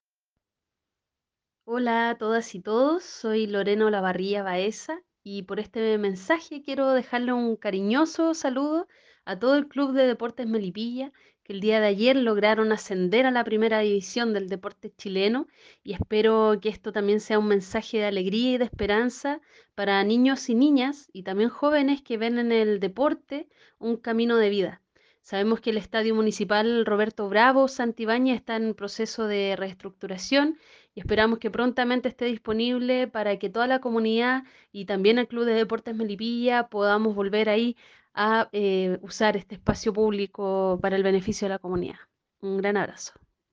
Por su parte, autoridades y candidatos para las elecciones de abril próximo quisieron entregar su saludo al programa radial Entre Portales: